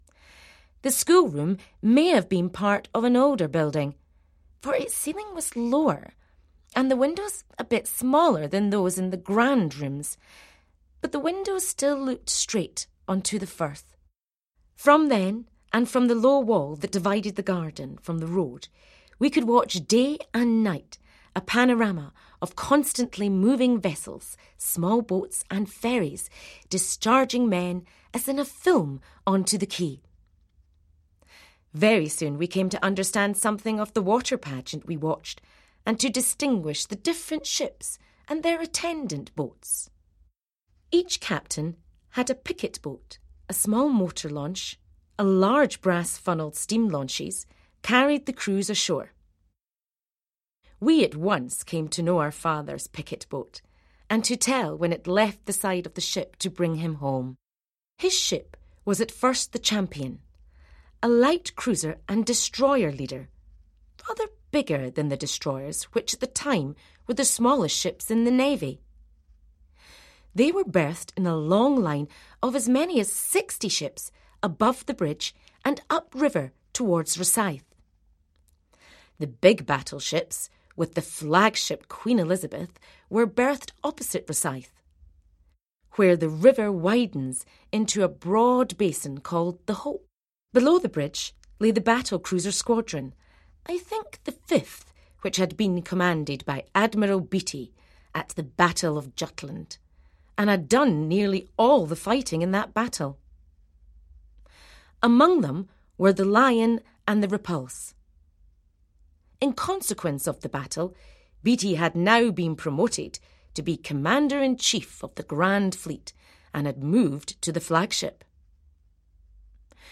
narrated